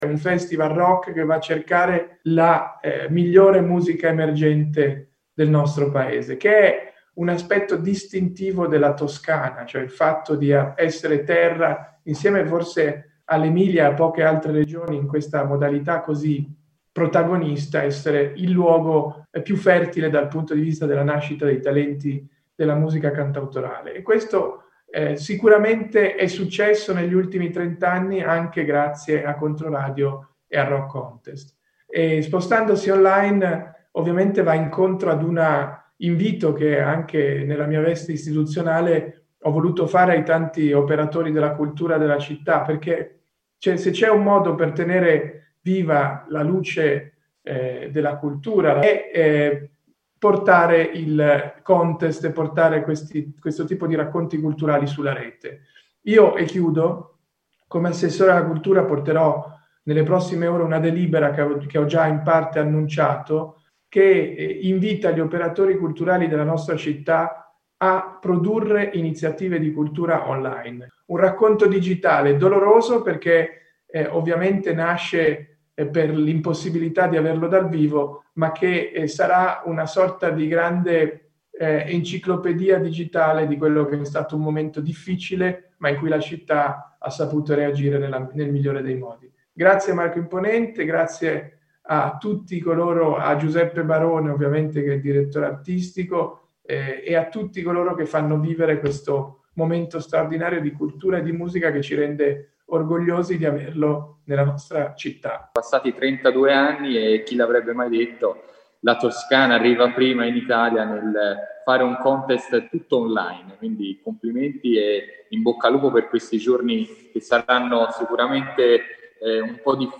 Interviste all’assessore alla Cultura di Firenze Tommaso Sacchi, Bernard Dika,
Consigliere del Presidente della Regione Toscana per le Politiche Giovanili e l’Innovazione e